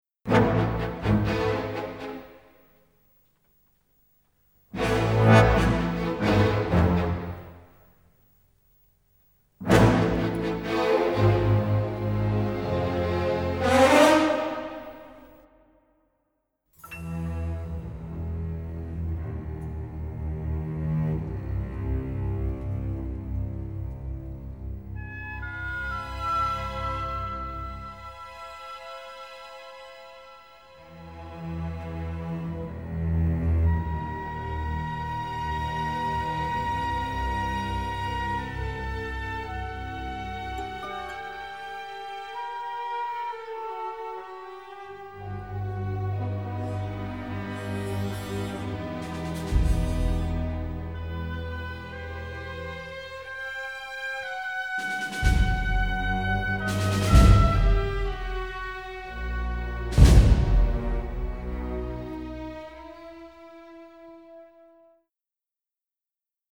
Trumpets, French horns keep everything at peak excitement.
Recorded in Germany.